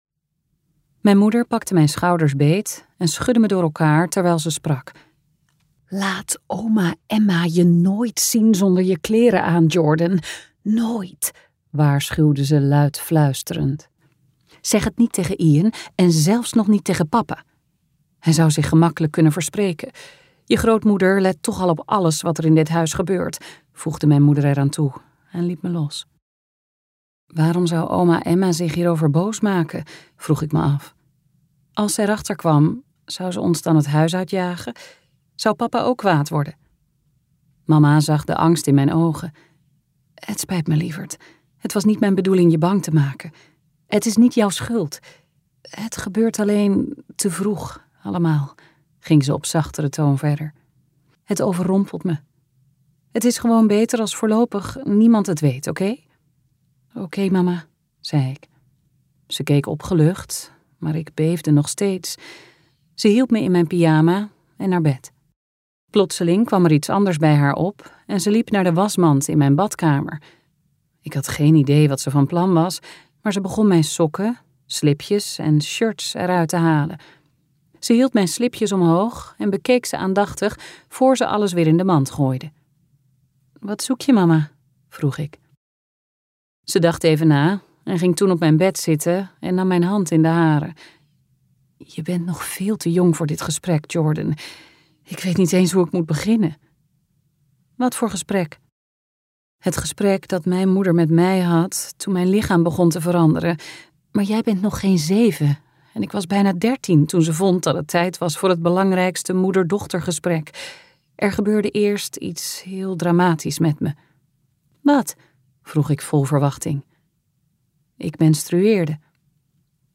Uitgeverij De Fontein | Vertrapte bloem luisterboek